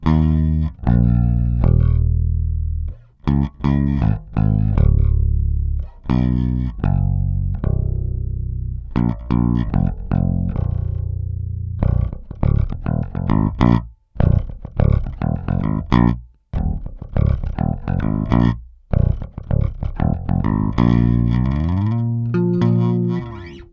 Takhle hraje můj JB (pasiv) při podladění o 1/2 tón. Struny ocel, staroba (několik let).
trsátko